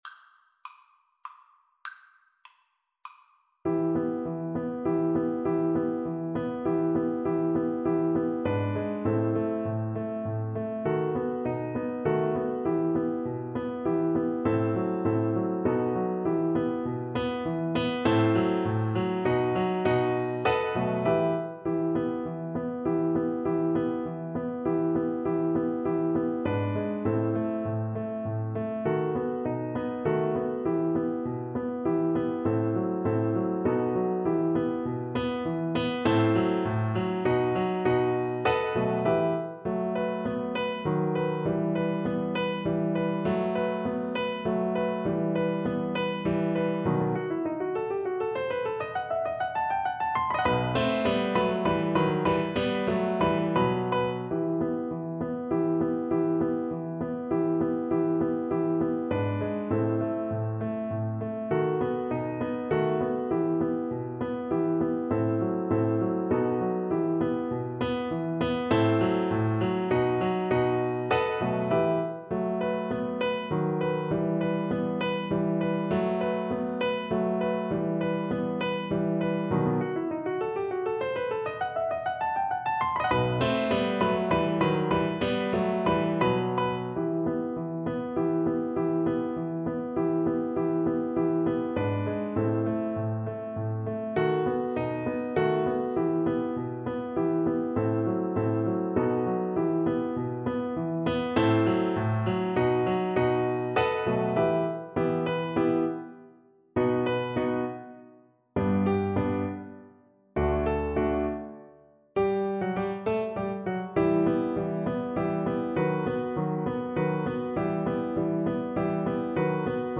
Classical Ogiński, Michał Kleofas Polonaise 'Pozegnanie Ojczyzny' Viola version
Viola
E minor (Sounding Pitch) (View more E minor Music for Viola )
Moderato
3/4 (View more 3/4 Music)
E4-C6
Classical (View more Classical Viola Music)